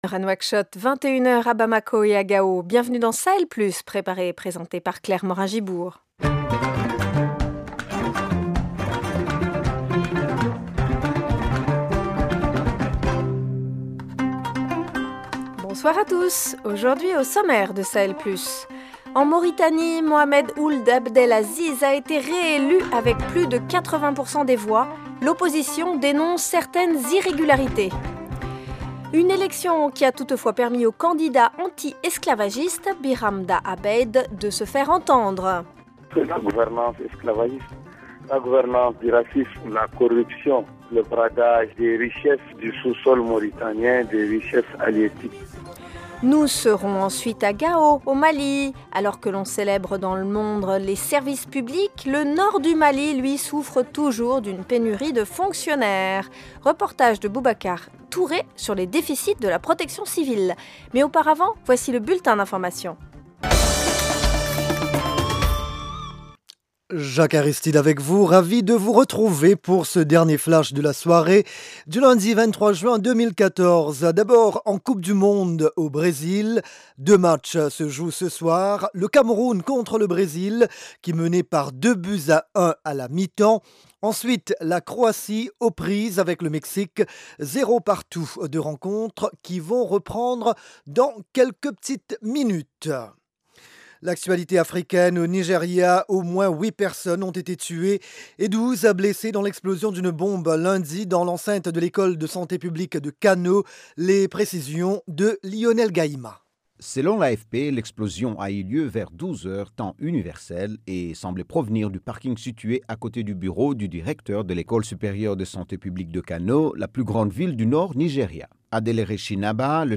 Journée internationale des services publics : reportage